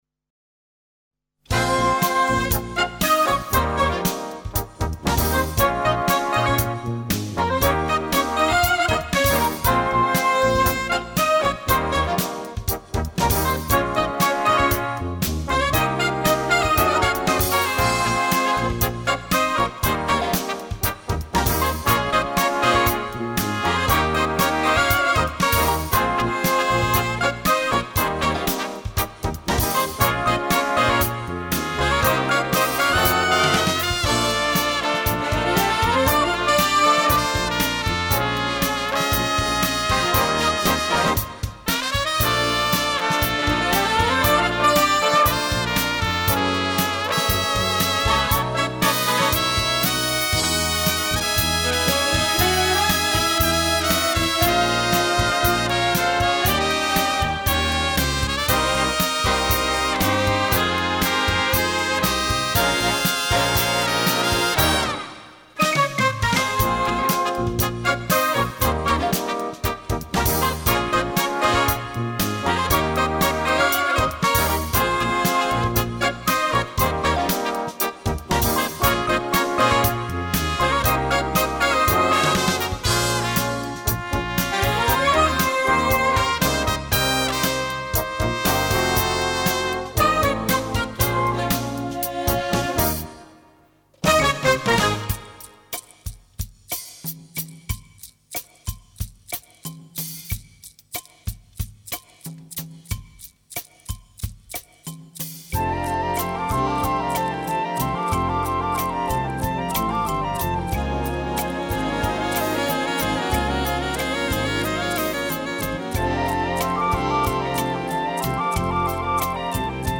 Partitions pour orchestre d'harmonie et - fanfare.